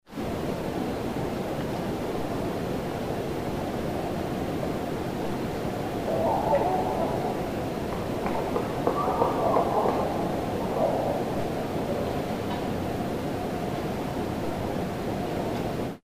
From the early morning of October 11, 2009 in the Adirondack Park, Warren Co., NY . A distant vocalization from across the pond starting at 0:06 to 0:12. Within this vocalization is a set of 7 knocking “whacks”. I am suspecting the vocalization is possibly from a Barred Owl, but that the knocking is from a different, unknown source.
Equipment used was a Zoom H2 recorder that was set out in a tree for the overnight.
ovrnght_10-11_clip_vocalizations-knocks.mp3